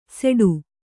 ♪ seḍu